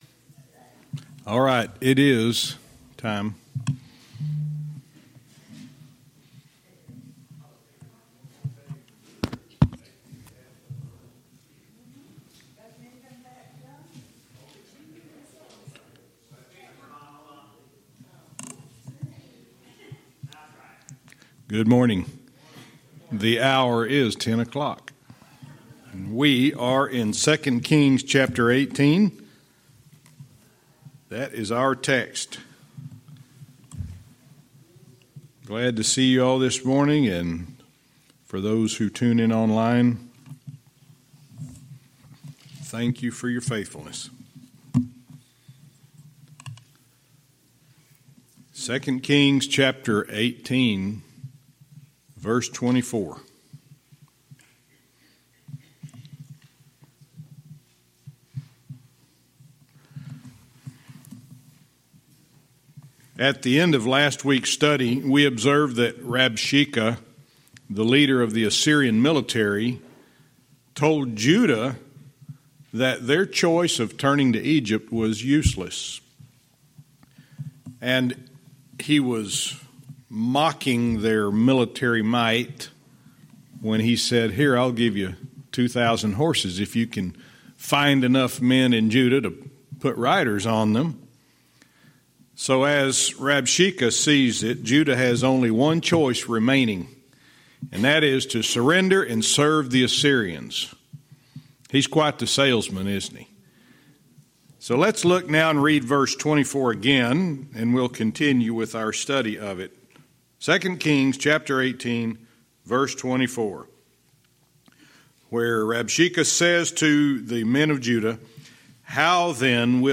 Verse by verse teaching - 2 Kings 18:24(cont)-25